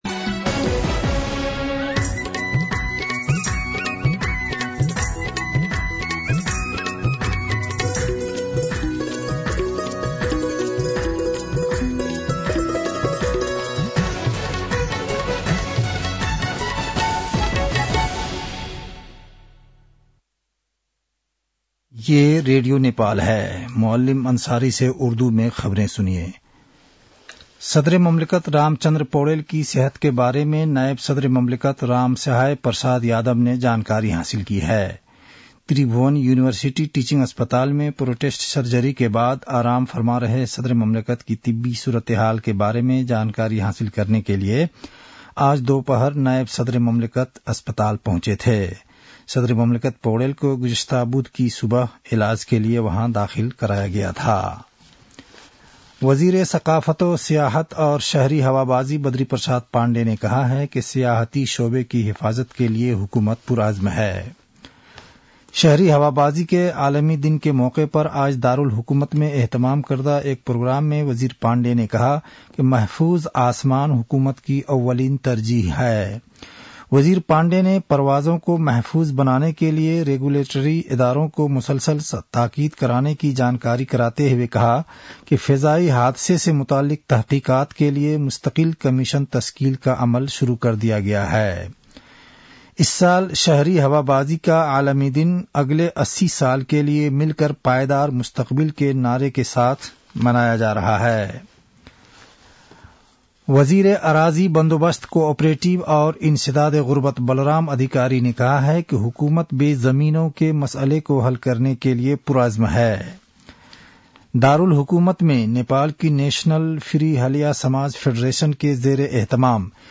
उर्दु भाषामा समाचार : २३ मंसिर , २०८१
Urdu-News-8-22.mp3